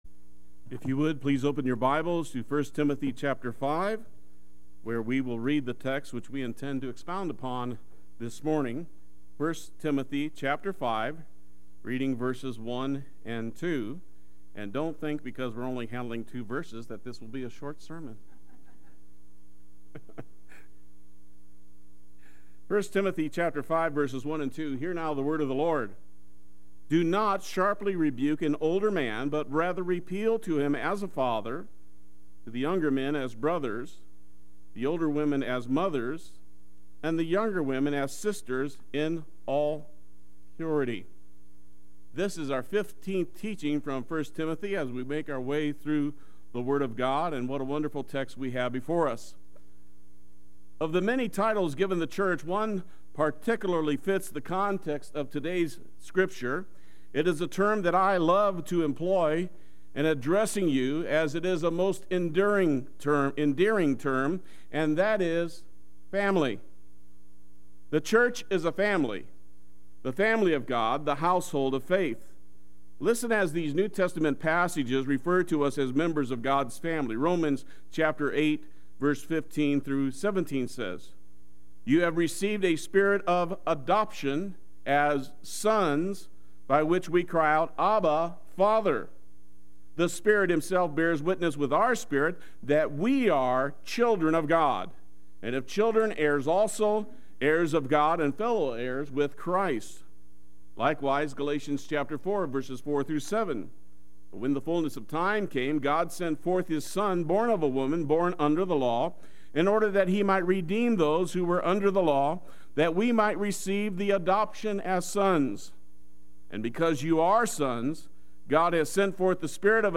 Play Sermon Get HCF Teaching Automatically.
Appeal…..In All Purity Sunday Worship